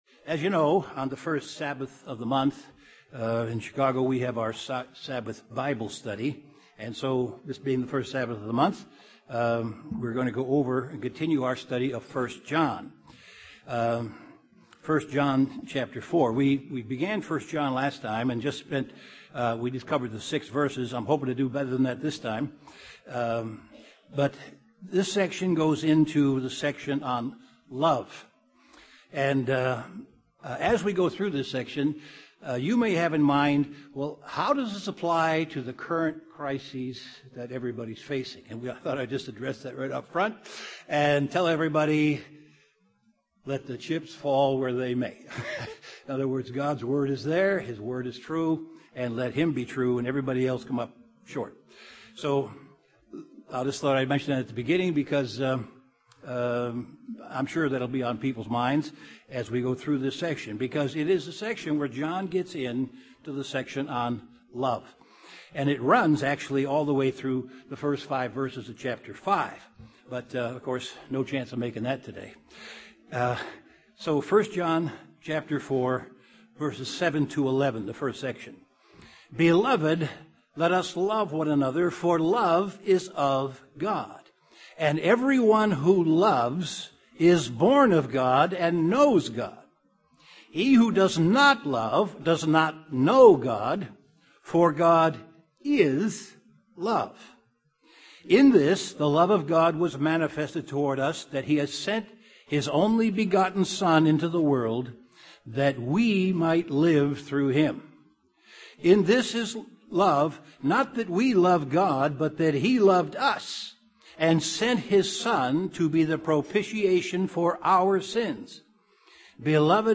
Continuation of an in-depth Bible study on the book of 1 John.
Given in Chicago, IL
UCG Sermon Studying the bible?